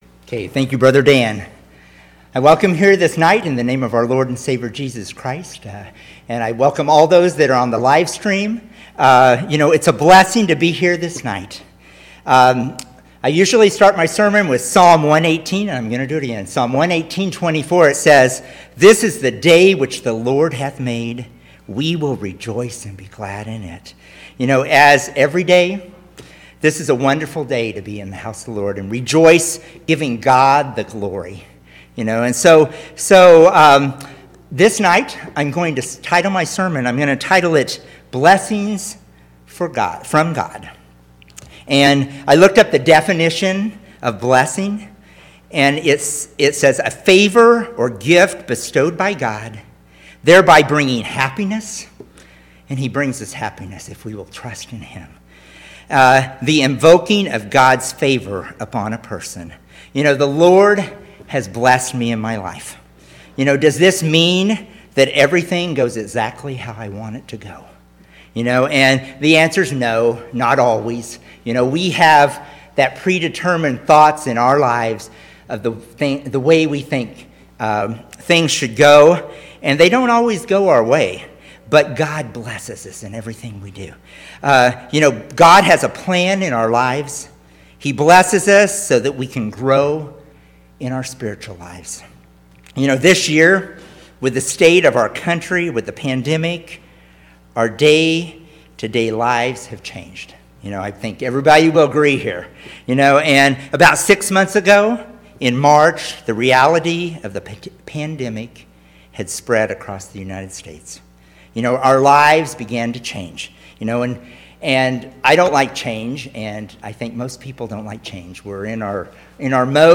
9/20/2020 Location: Temple Lot Local Event